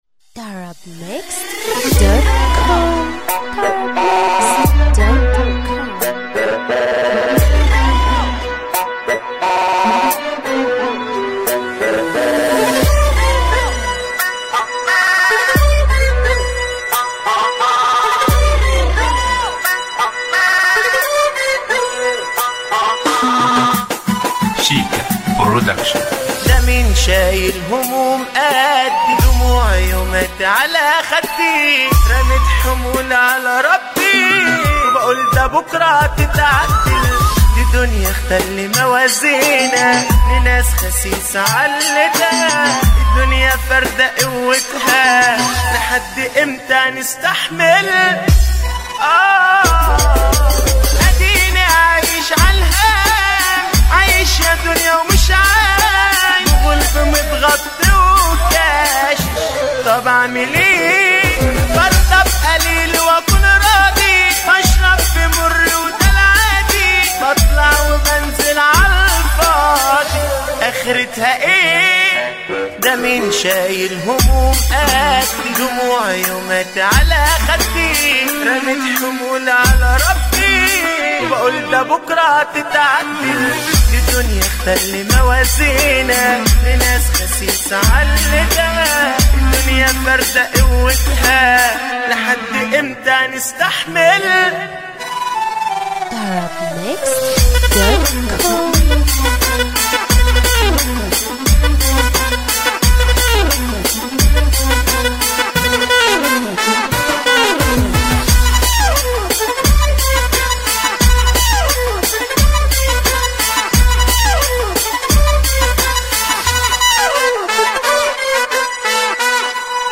اغانى شعبى